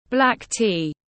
Trà đen tiếng anh gọi là black tea, phiên âm tiếng anh đọc là /ˌblæk ˈtiː/
Black tea /ˌblæk ˈtiː/